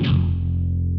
TAPOW GUITAR 1.wav